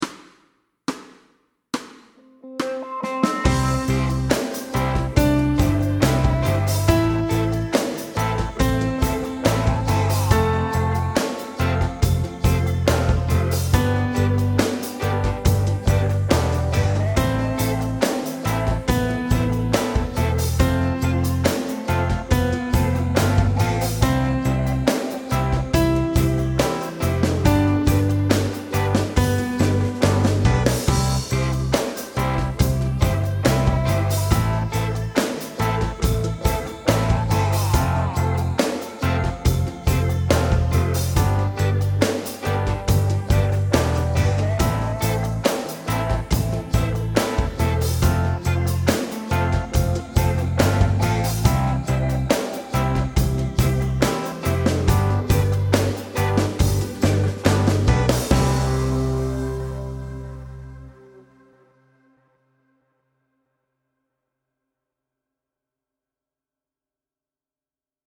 Medium C instr (demo)